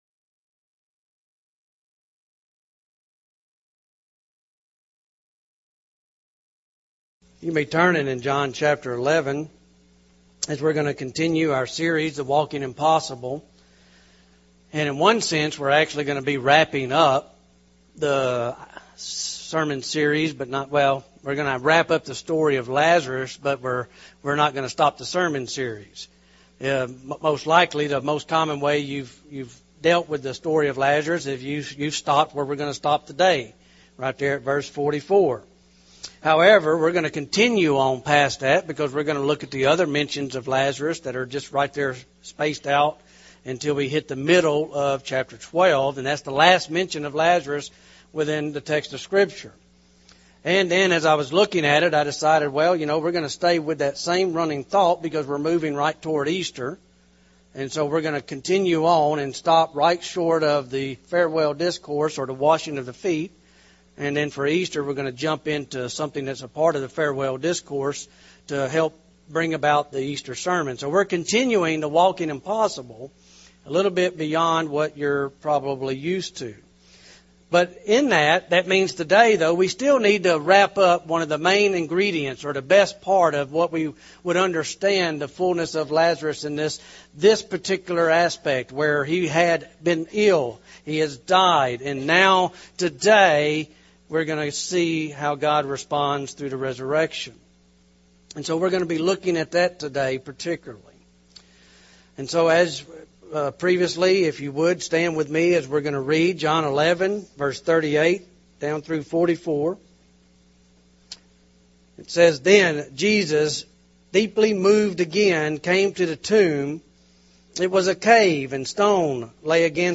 Sermons 2016-2017